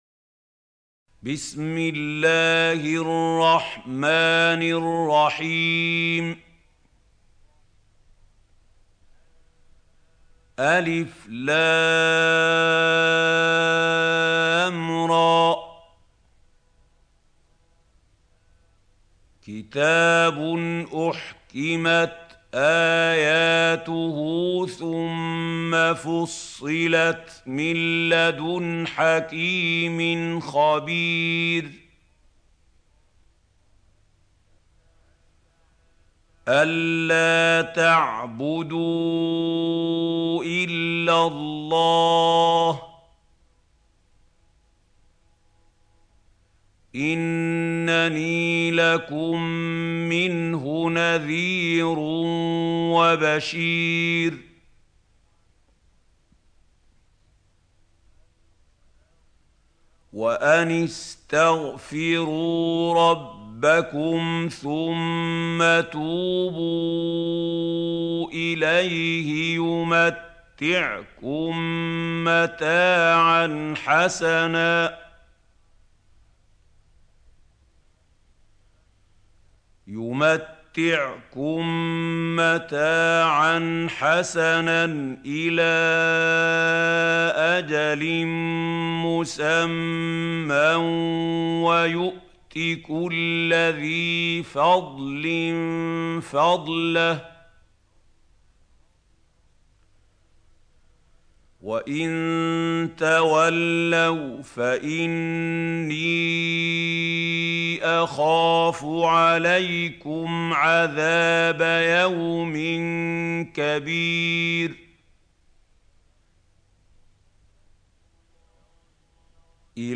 سورة هود | القارئ محمود خليل الحصري - المصحف المعلم